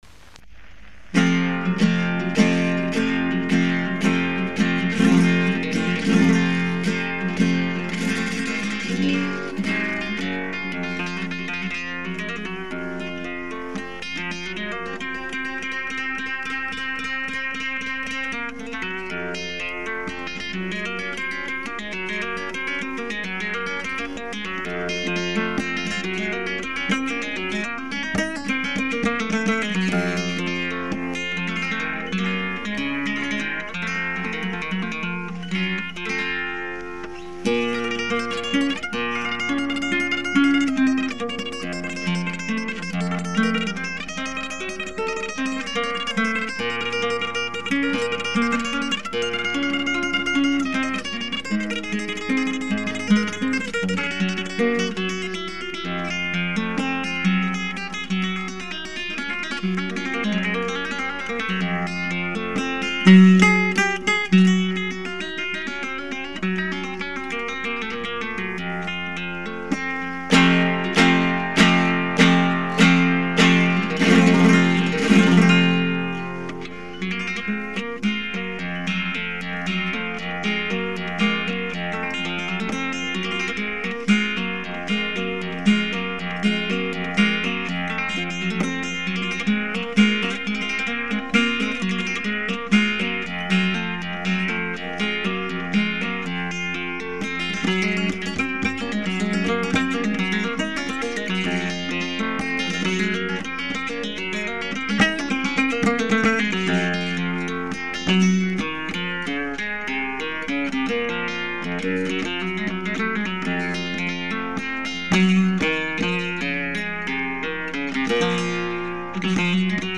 soleá